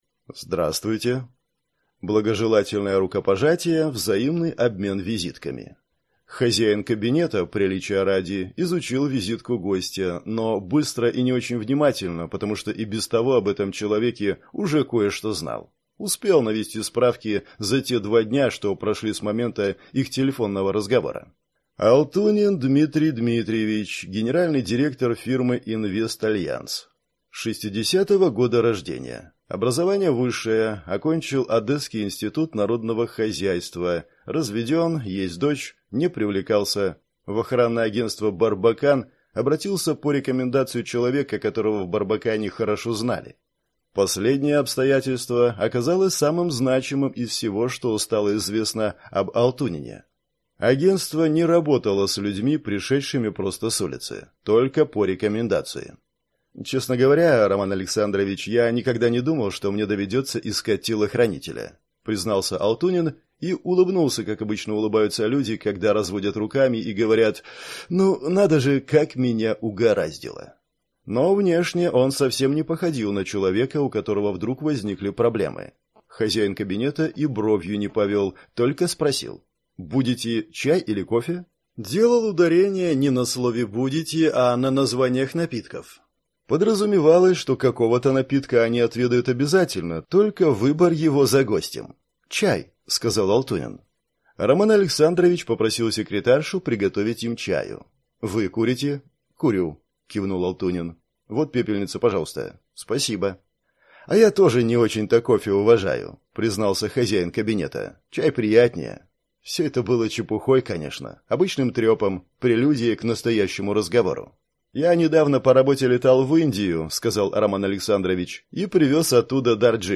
Аудиокнига Я – телохранитель. Киллер к юбилею | Библиотека аудиокниг